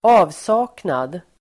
Uttal: [²'a:vsa:knad]